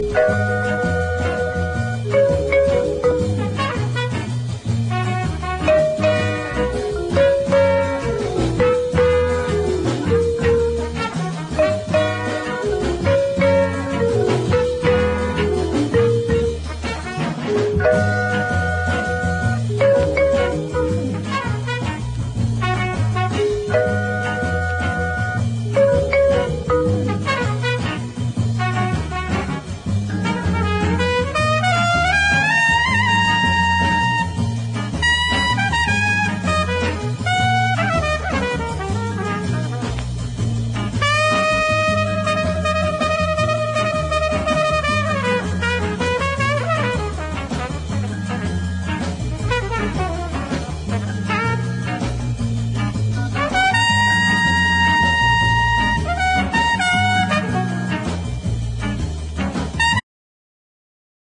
なベース・ラインとパーカッシヴなサウンドでGARAGEファンにも人気の名曲